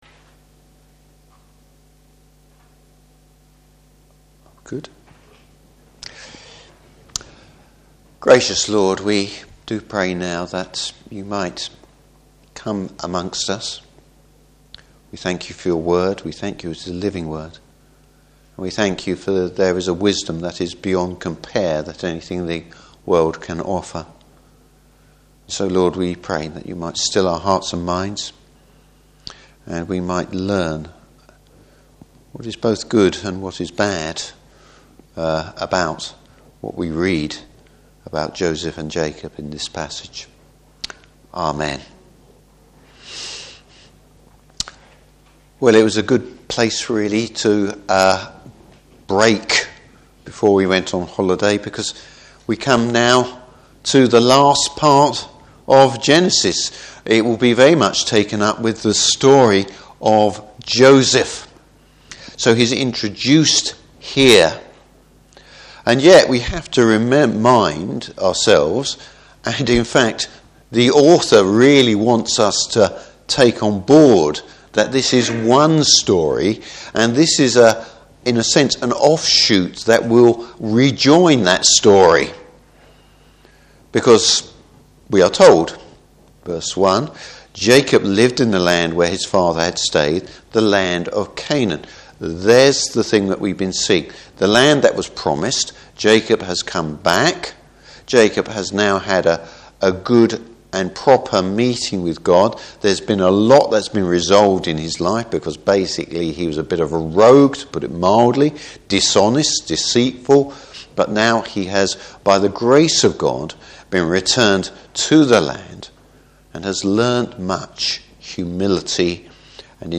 Service Type: Evening Service Joseph’s lack of spiritual wisdom in using his gift.